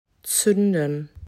z.B. zünden
zuenden.m4a